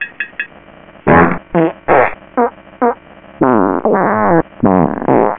farts
(6khz,8bit,mono) 32 KB
Similar to Danube, but far far stinkier.
fart_song.wav